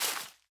Minecraft Version Minecraft Version snapshot Latest Release | Latest Snapshot snapshot / assets / minecraft / sounds / block / leaf_litter / break2.ogg Compare With Compare With Latest Release | Latest Snapshot